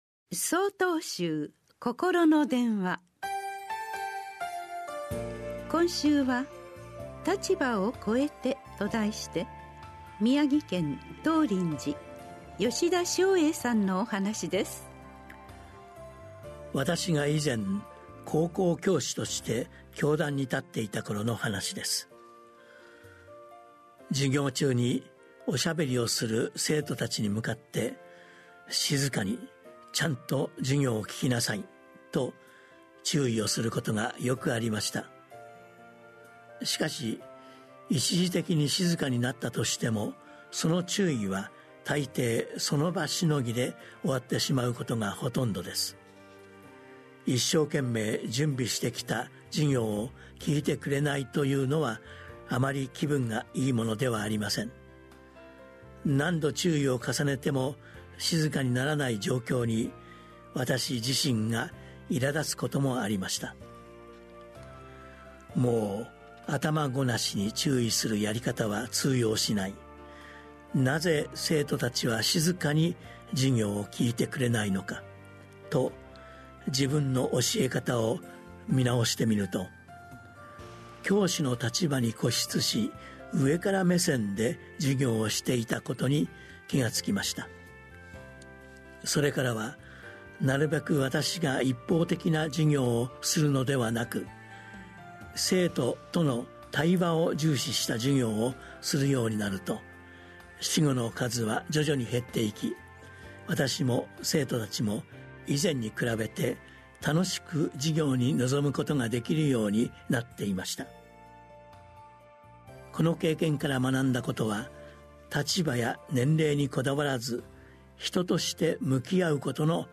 曹洞宗がお届けするポッドキャスト配信法話。 禅の教えを踏まえた「ほとけの心」に関するお話です。